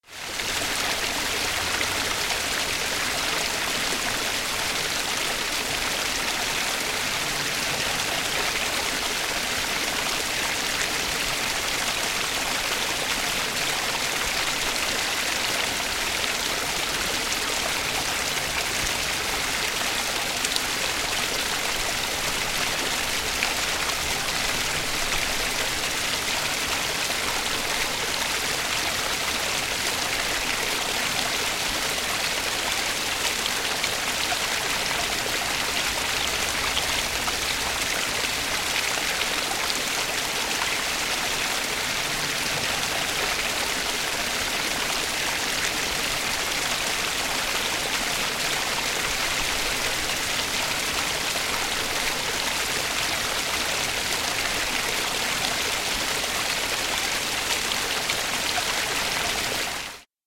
Tonos gratis para tu telefono – NUEVOS EFECTOS DE SONIDO DE AMBIENTE de LLUVIA DESCARGA GRATIS
Ambient sound effects
Descargar EFECTO DE SONIDO DE AMBIENTE LLUVIA DESCARGA GRATIS - Tono móvil
Lluvia_Descarga_gratis.mp3